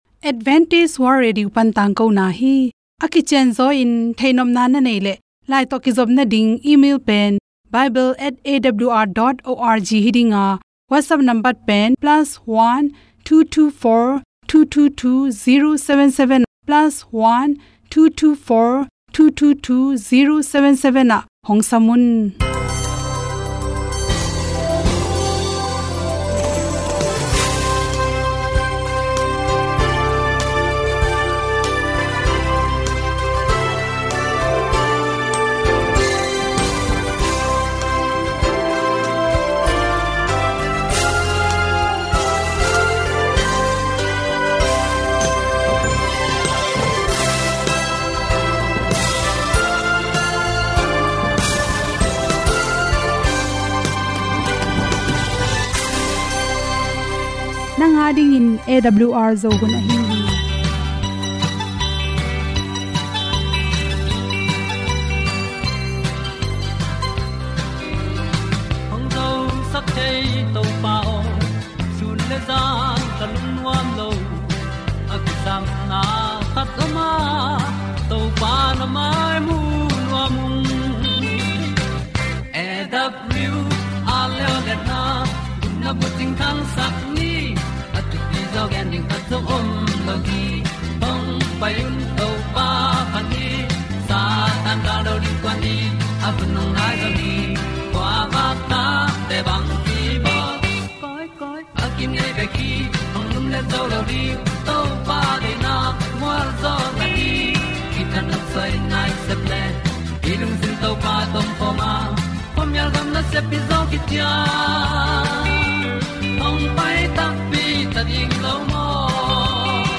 Chin Gospel Songs.